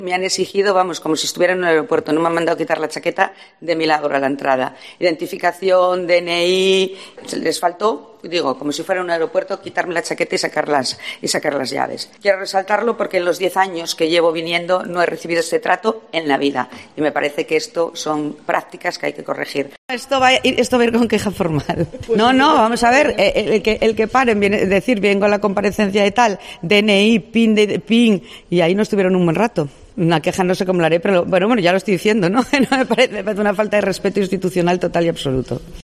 Así explicaba la Fiscal su incidente a la entrada del parlamento